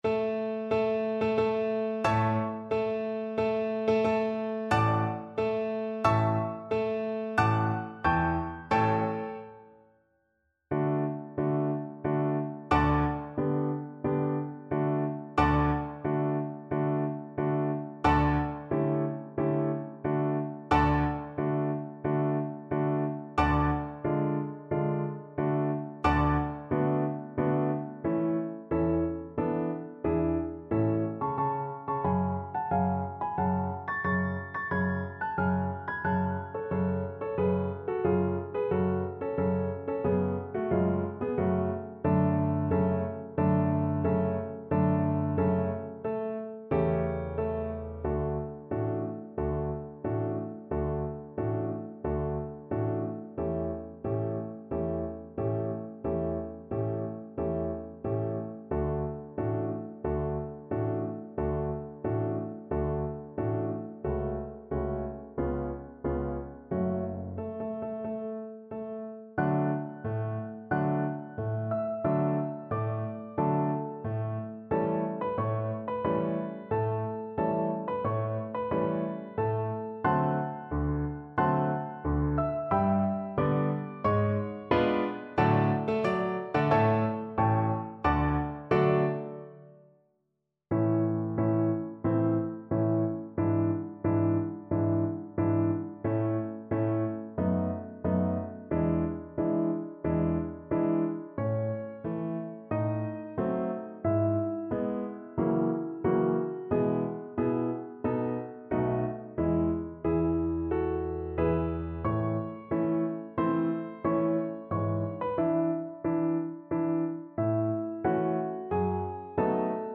Allegro marziale (View more music marked Allegro)
4/4 (View more 4/4 Music)
Db5-A6
Classical (View more Classical Voice Music)